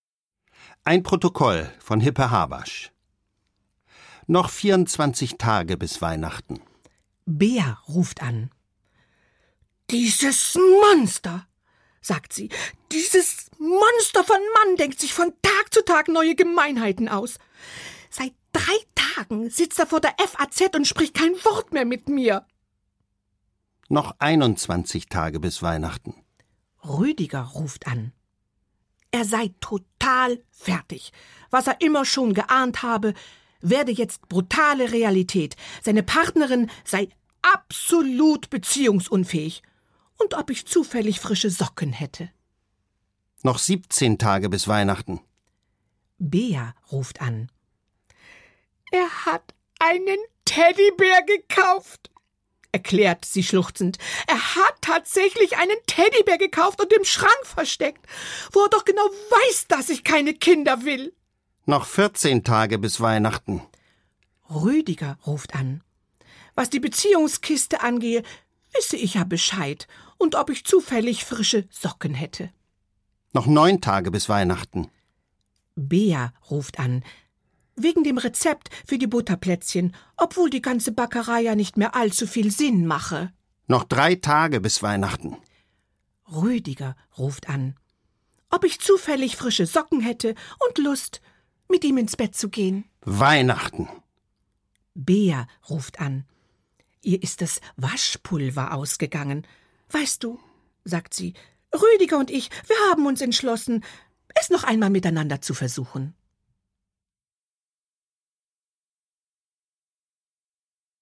Schlagworte Advent • Adventszeit • Hörbuch • Hörbuch; Literaturlesung • Weihnachten • Weihnachtsgeschichten / Weihnachtserzählungen • Weihnachtszeit